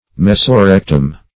Search Result for " mesorectum" : The Collaborative International Dictionary of English v.0.48: Mesorectum \Mes`o*rec"tum\, n. [Meso- + rectum.]
mesorectum.mp3